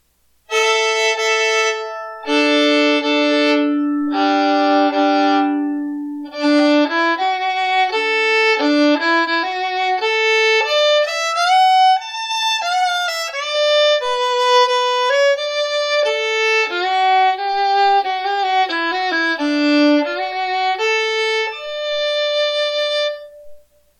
New Violin / Fiddle Outfit with case & bow - $225.00
In general, these have a full tone tone quality with nice depth. Most are medium loud to loud in volume.